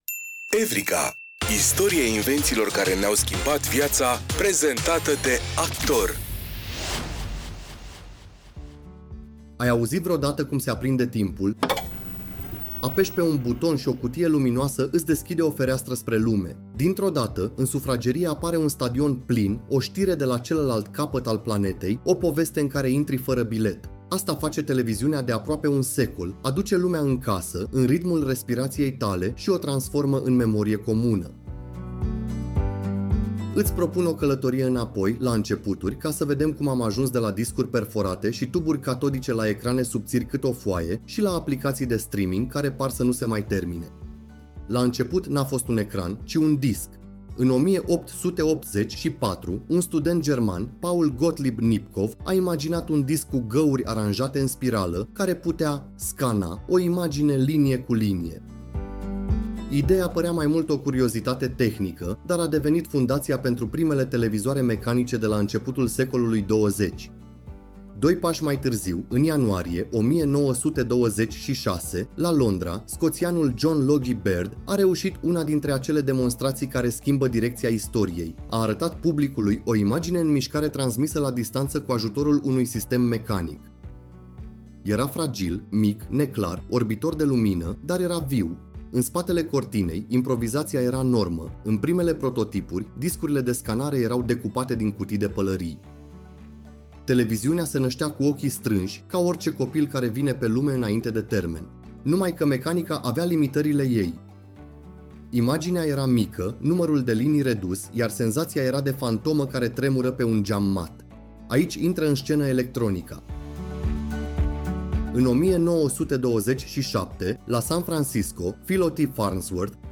În fiecare episod, descoperim poveștile oamenilor și ideilor care au schimbat felul în care trăim, comunicăm și gândim. Cu un ton cald și curios, „Evrika” transformă știința și tehnologia în aventuri umane, pe înțelesul tuturor. Producția este realizată cu ajutorul inteligenței artificiale, combinând cercetarea documentară cu narațiunea generată și editată creativ de echipa SOUNDIS România.